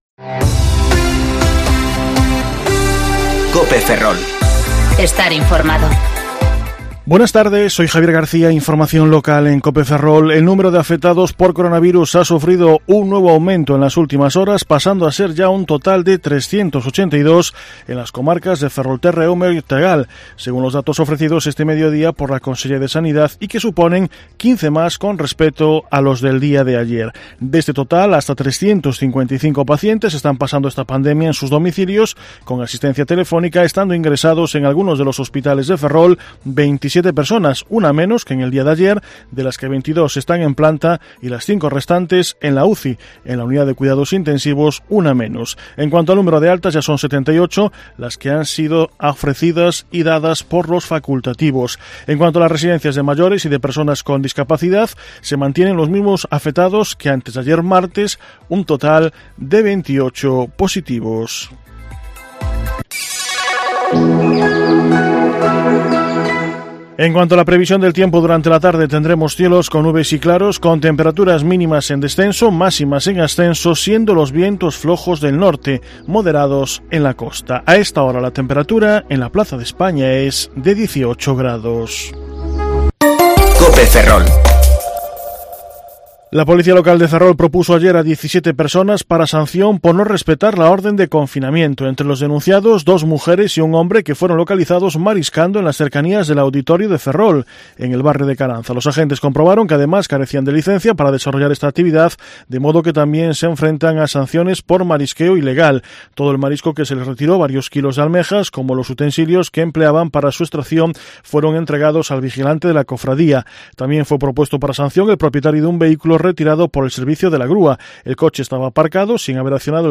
Informativo Mediodía COPE Ferrol 23/4/2020 (De 14,20 a 14,30 horas)